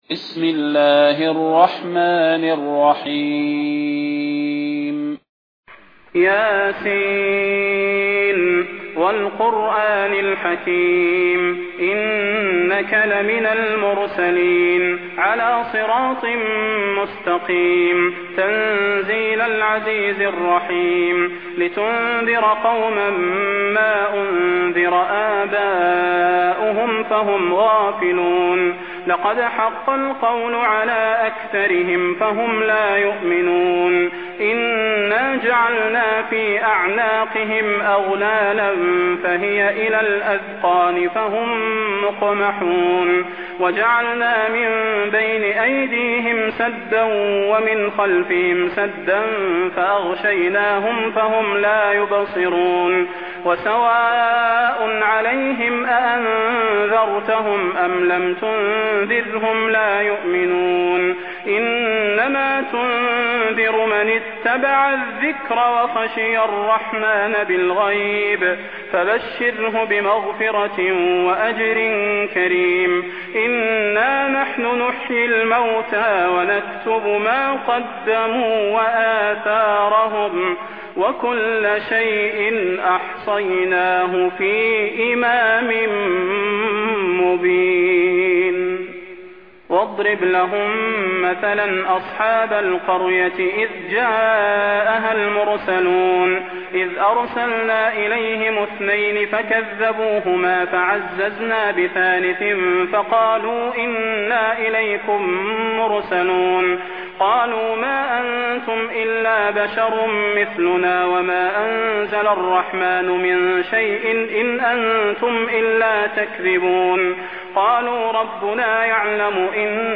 المكان: المسجد النبوي الشيخ: فضيلة الشيخ د. صلاح بن محمد البدير فضيلة الشيخ د. صلاح بن محمد البدير يس